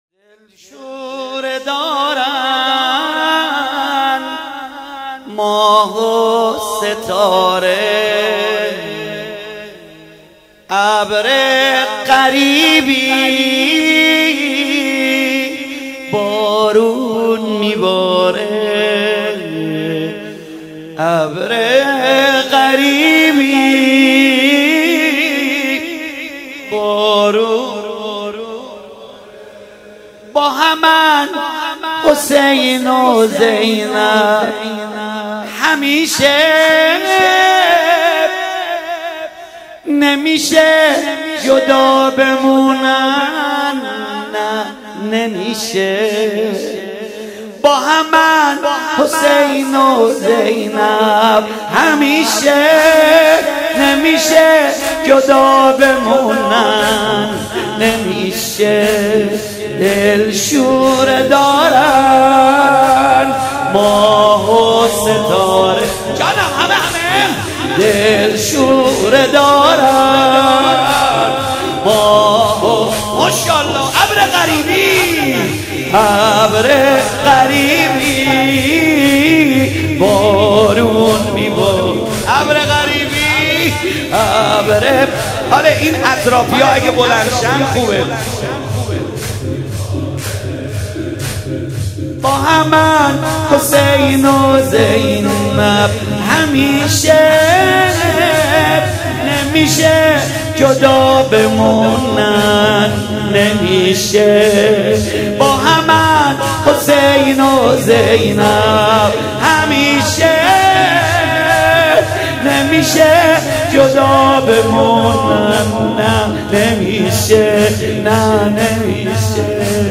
روضه محمود کریمی